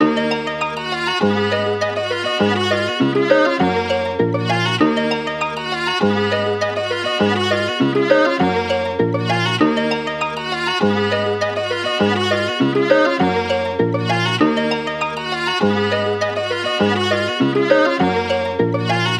Each sample is meticulously crafted to help you achieve the same hard-hitting and soulful vibe that Divine is known for.
Gully-Loops-Gully-Melody-Loop-BPM-100-D-Min.wav